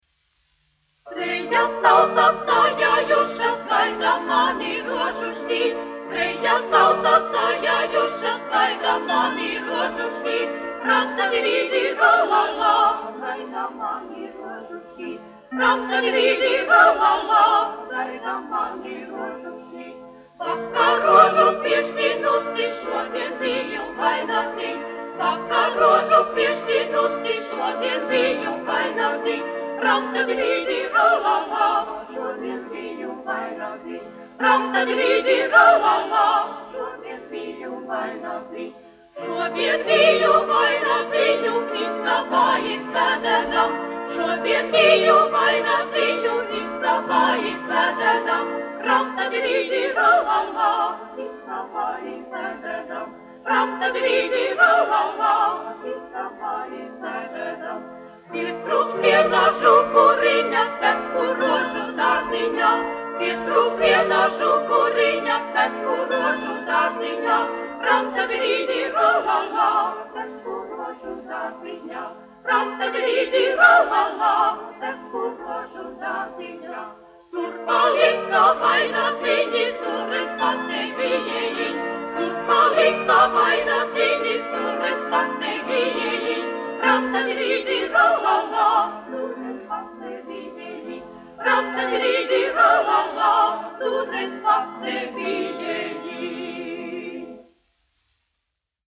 Trejas tautas sajājušas : latviešu tautas dziesma
Latvijas Radio koklētāju ansamblis, izpildītājs
Latvijas Radio sieviešu vokālais sekstets, izpildītājs
1 skpl. : analogs, 78 apgr/min, mono ; 25 cm
Latviešu tautasdziesmas